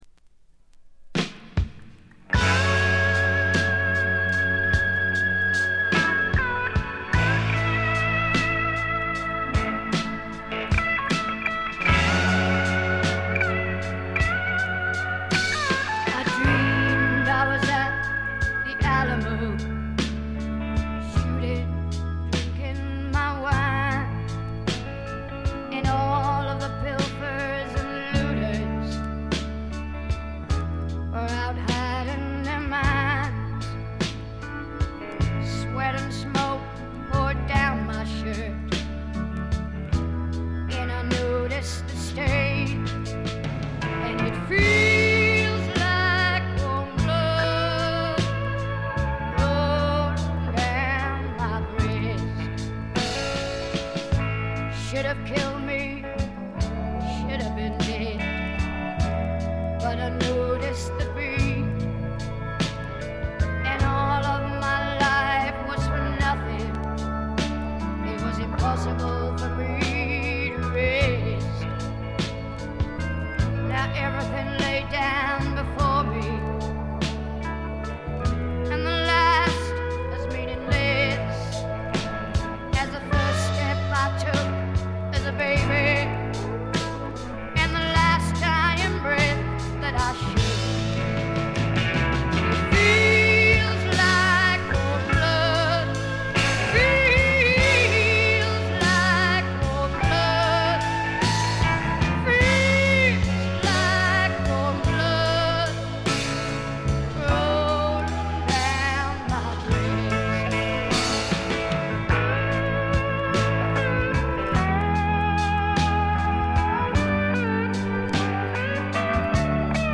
ホーム > レコード：英国 スワンプ
録音はロンドンとナッシュビルで録り分けています。
試聴曲は現品からの取り込み音源です。